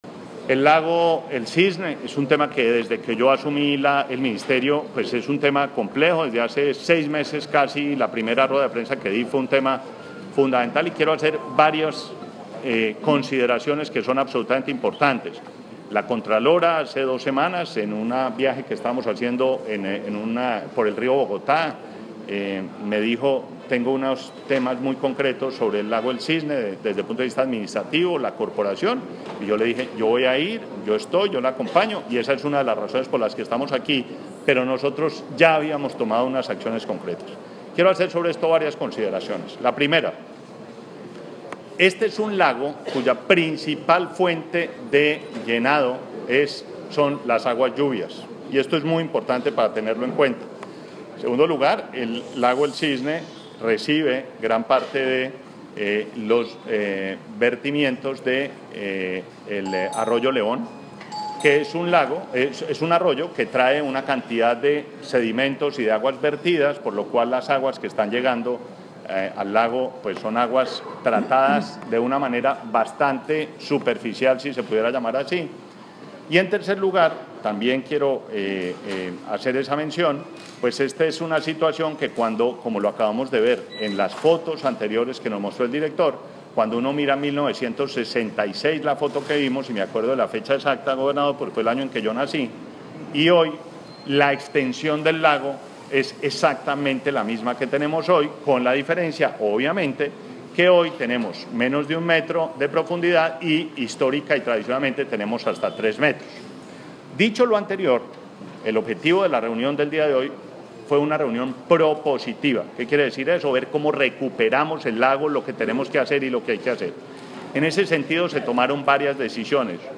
Declaraciones del Ministro de Ambiente, Gabriel Vallejo López